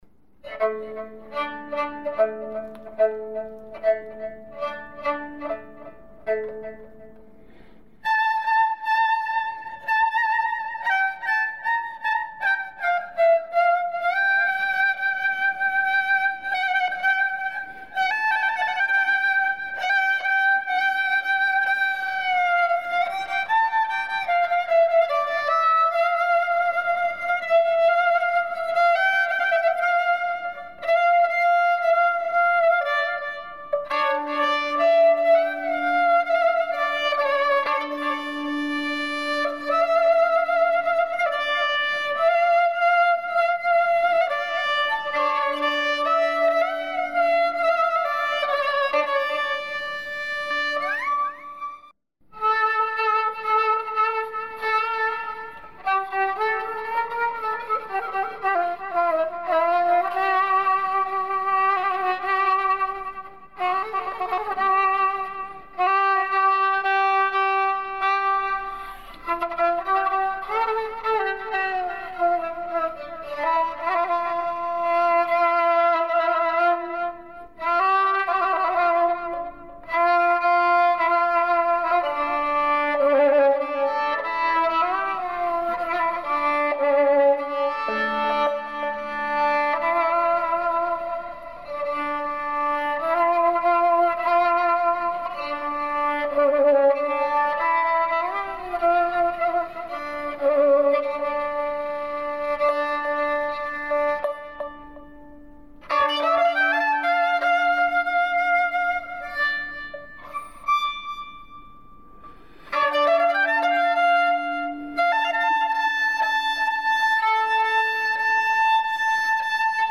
تک نوازی کمانچه
تک نوازی کمانچه در آواز اصفهان